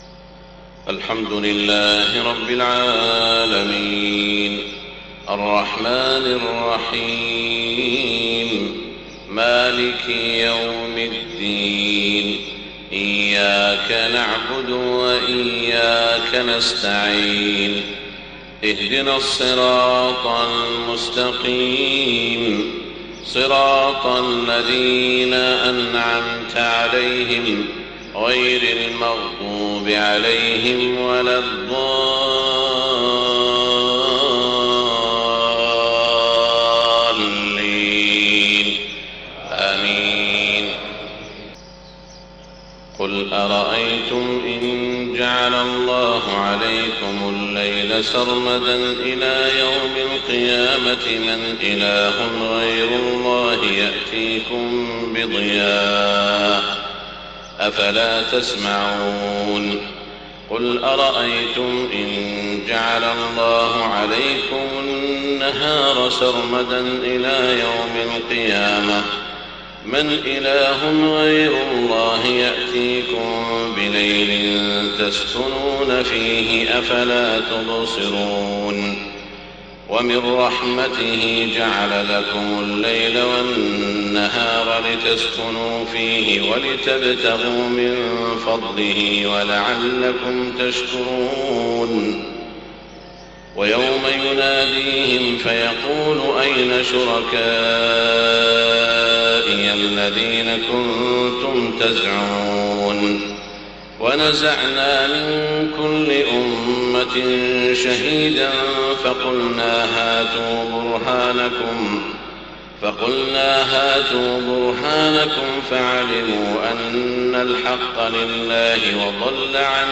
صلاة الفجر 21 صفر 1430 هـ من سورة القصص 71-88 > 1430 🕋 > الفروض - تلاوات الحرمين